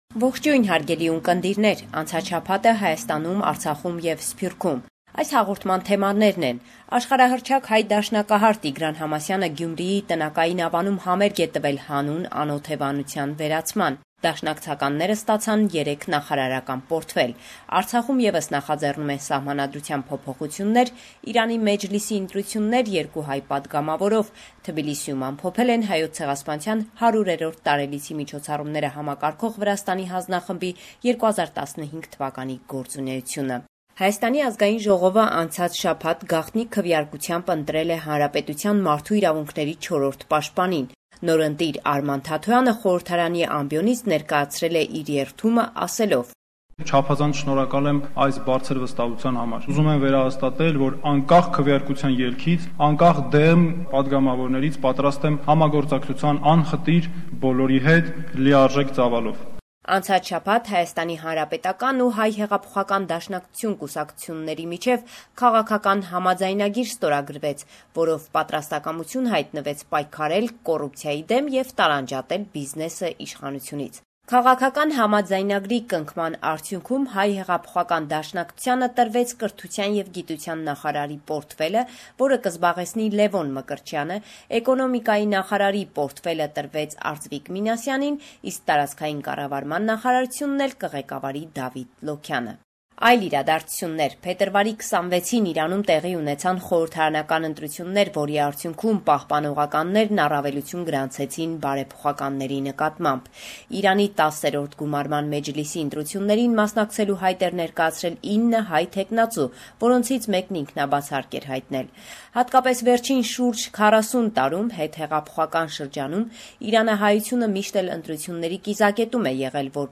LATEST NEWS – 1 March 2015